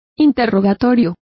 Complete with pronunciation of the translation of inquisition.